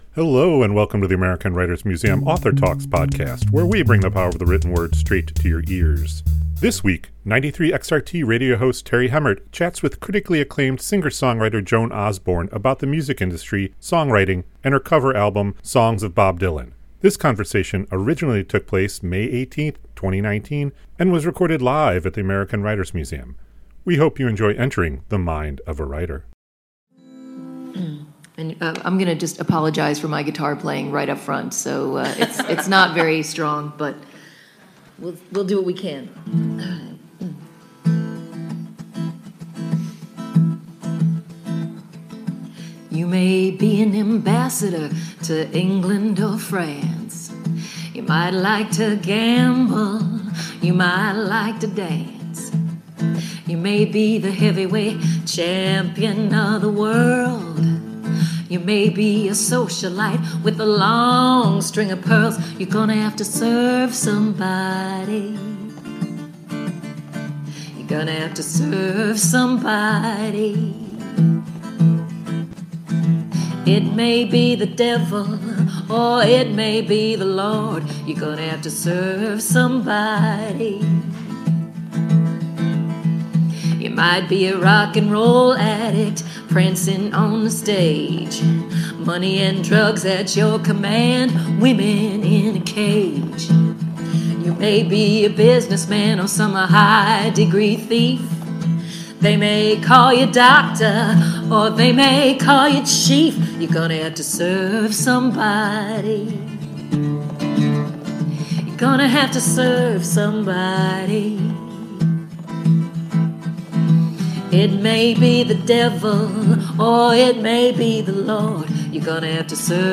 This conversation originally took place May 18th, 2019 and was recorded live at the American Writers Museum.